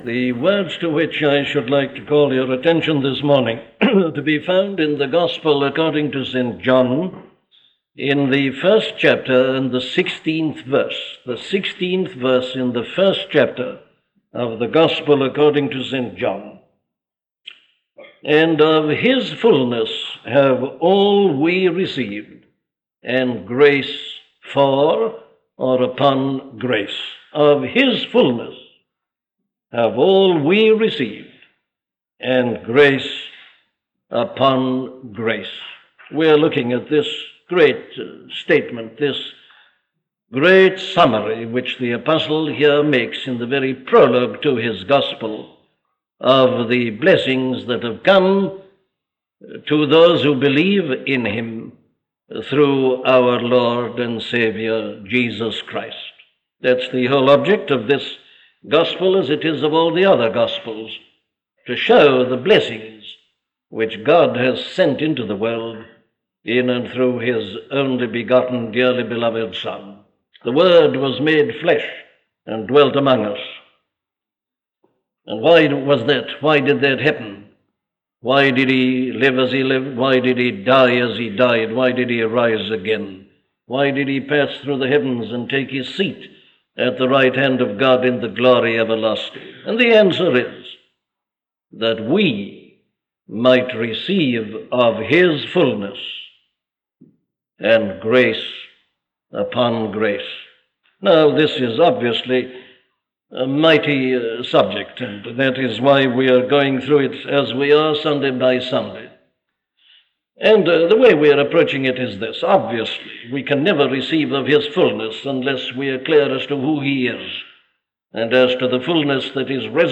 The Quest of Man - a sermon from Dr. Martyn Lloyd Jones
Listen to the sermon on John 1:16 'The Quest of Man' by Dr. Martyn Lloyd-Jones